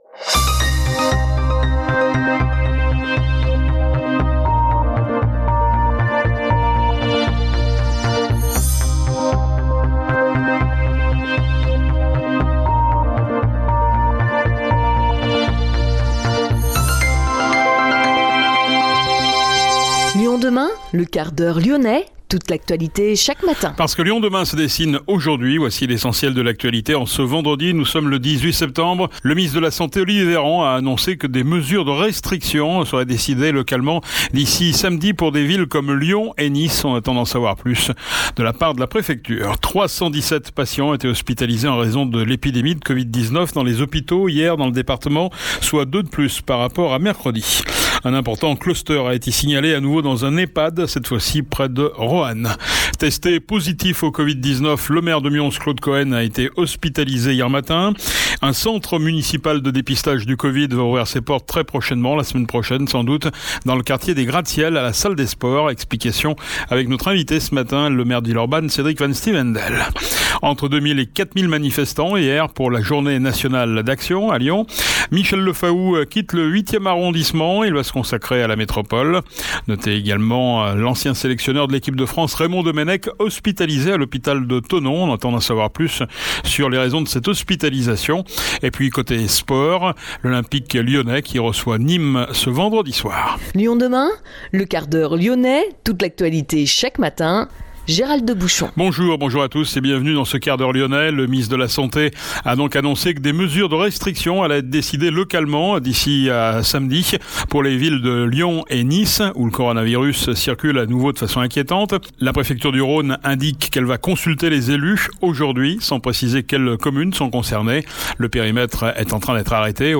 Notre invité : le maire de Villeurbanne Cédric van Styvendael
Le Quart-d’Heure Lyonnais est aussi diffusé en FM sur Pluriel 91,5, Salam 91,1, Judaïca 94,5 et Arménie 102,6